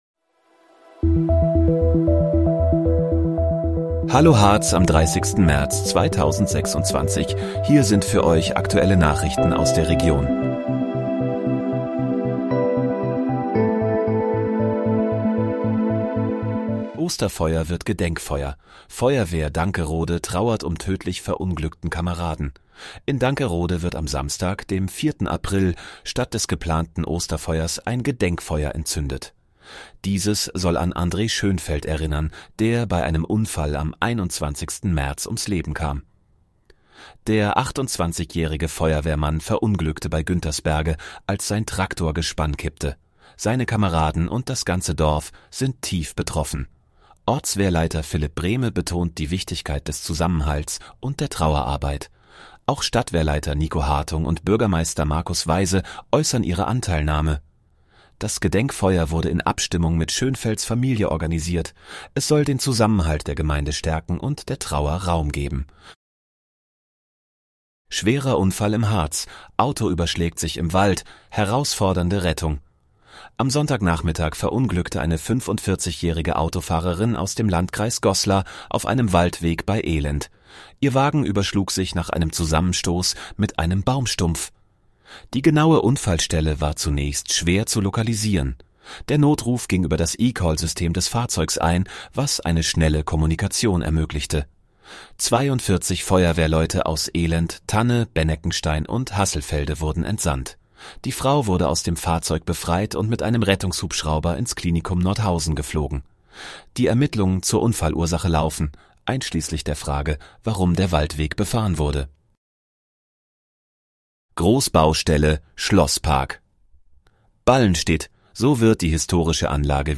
Hallo, Harz: Aktuelle Nachrichten vom 30.03.2026, erstellt mit KI-Unterstützung